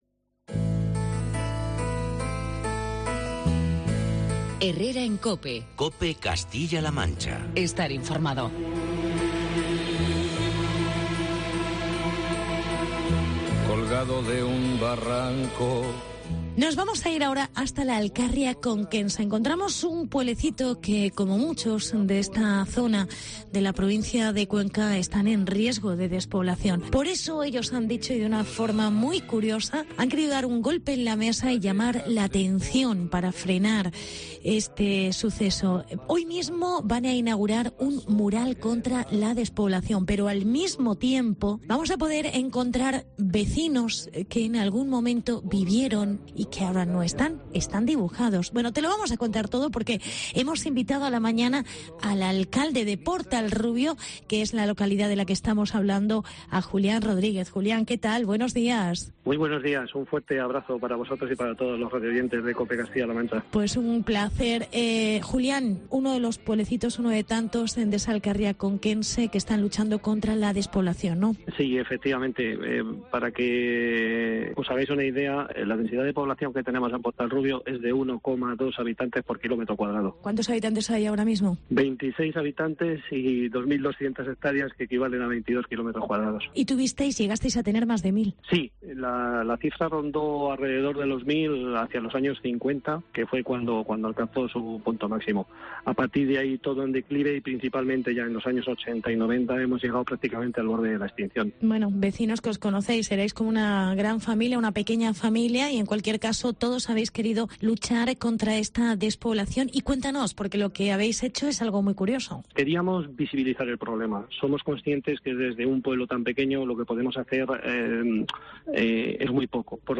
Entrevista con el alcalde: Julián Rodríguez